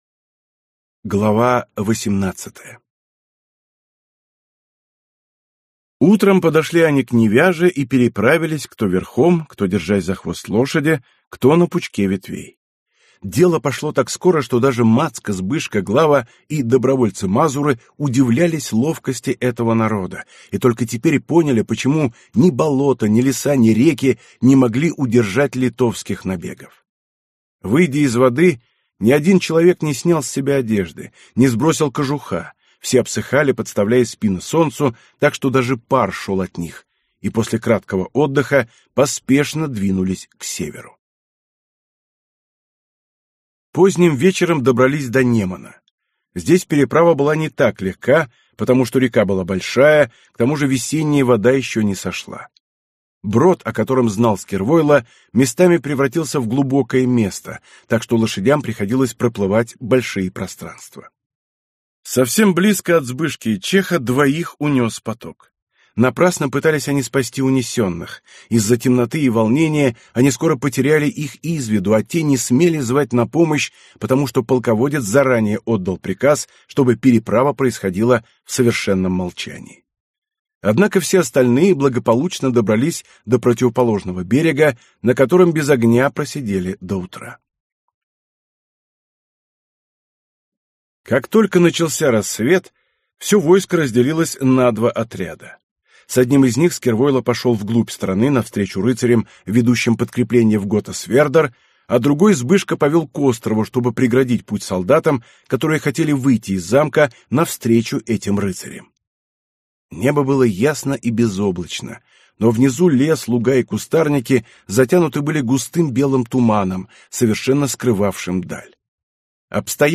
Аудиокнига Крестоносцы. Часть 3 | Библиотека аудиокниг